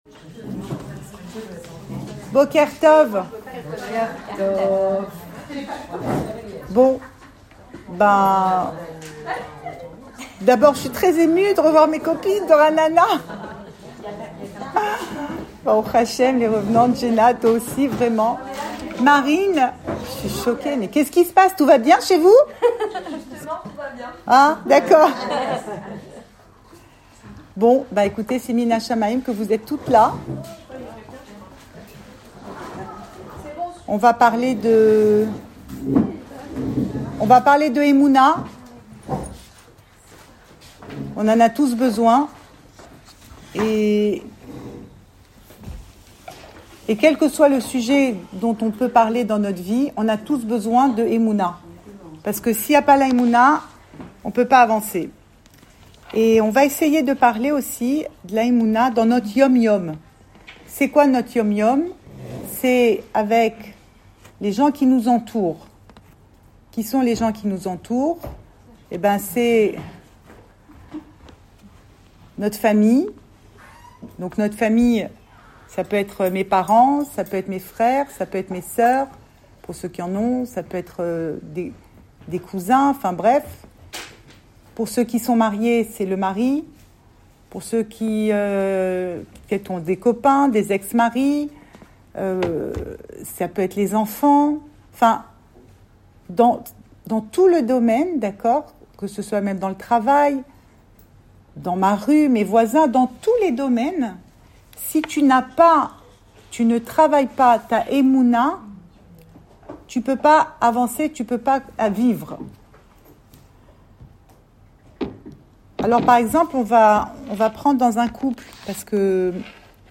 Cours audio
Enregistré à Tel Aviv